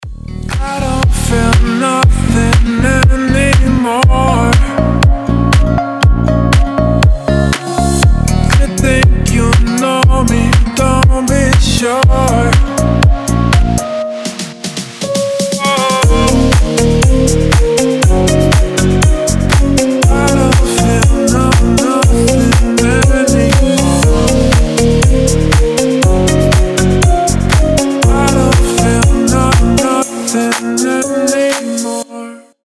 • Качество: 320, Stereo
красивые
deep house
мелодичные